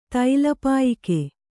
♪ taila pāyike